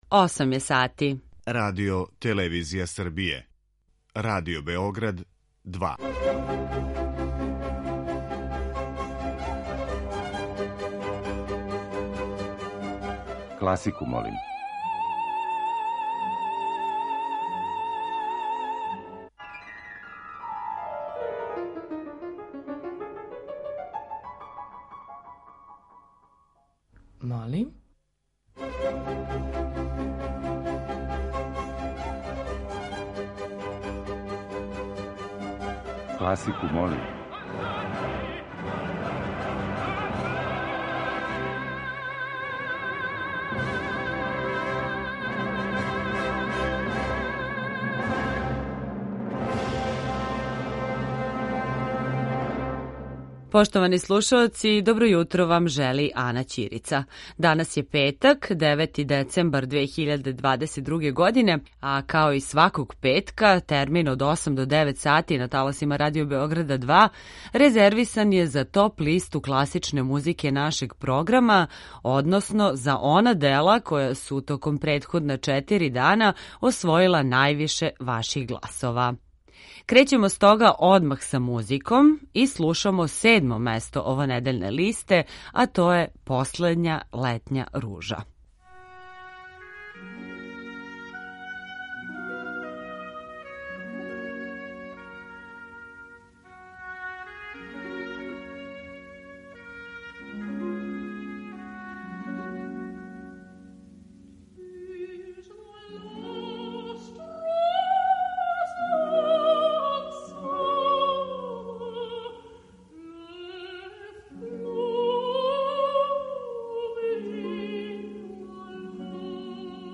klasika.mp3